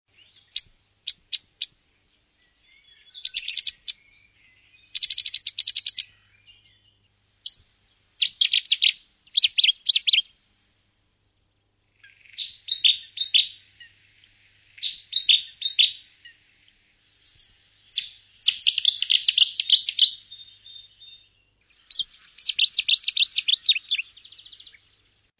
Ruiseñor bastardo
Cettia Cetti
CANTO
cantoRuisenorBastardo.mp3